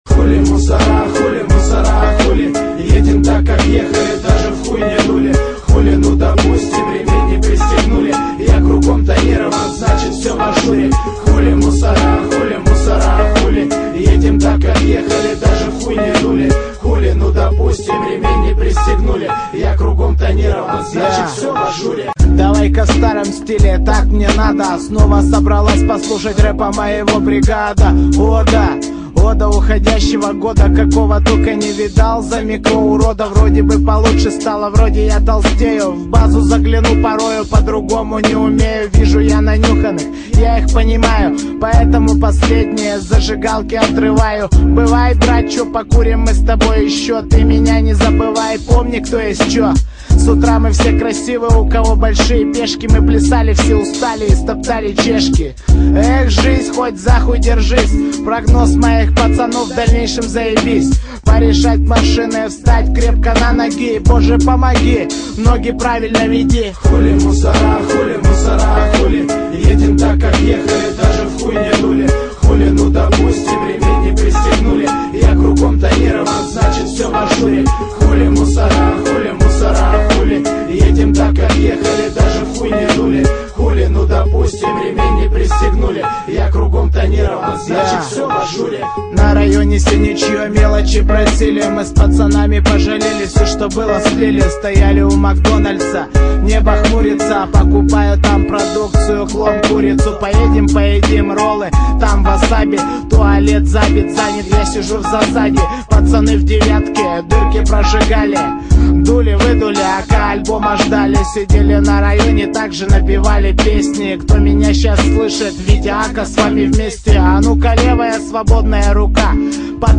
Русский реп